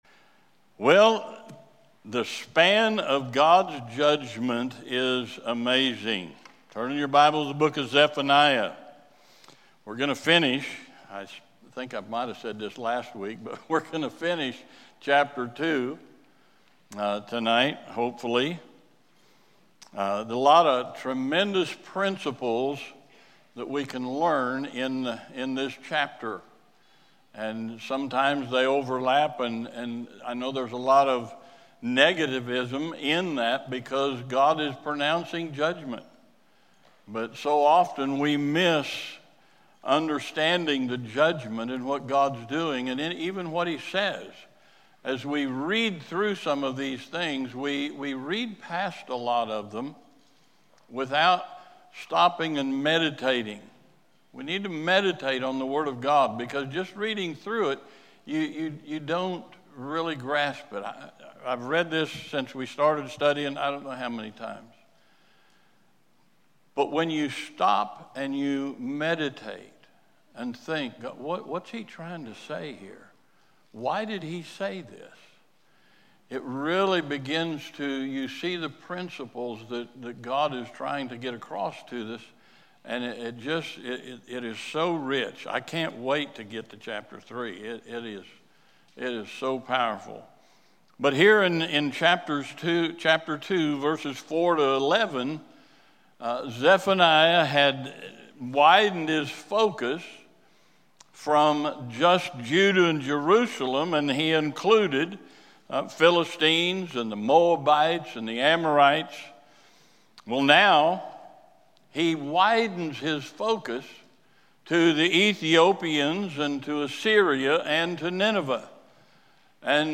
This sermon challenges you to seek the Lord, live holy, and separate from the world’s ways to avoid judgment and be a light for Christ.